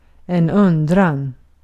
Uttal
Uttal US
IPA : /ˈkwɛs.tʃən/